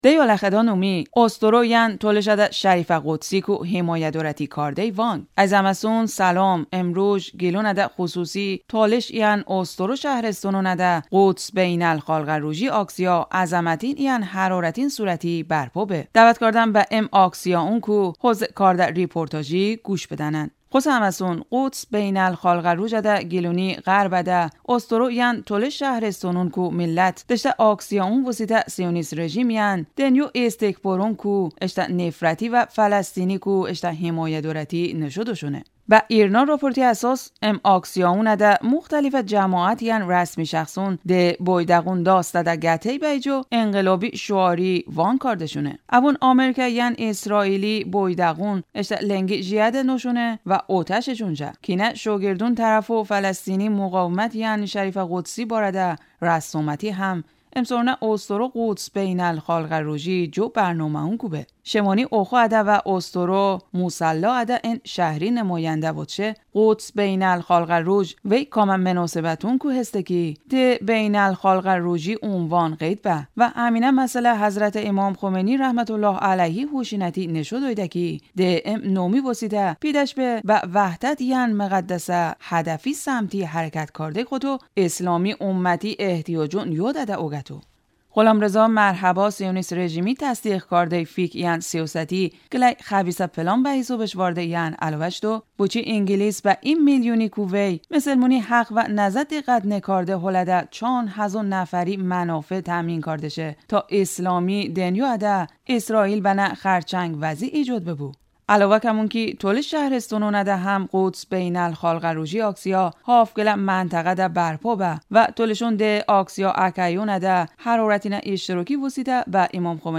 Dəvət kardəm bə ım aksiyaonku hozzı kardə reportaji quş bıdənən.